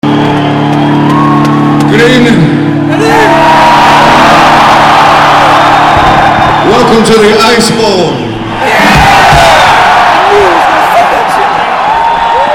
The weather reports foretold it would be a cold night, but by the end of the Alpine show in the middle of nowhere (East Troy, Wisconsin), it was 28 degrees and there was slippery frost on the grass on the lawn.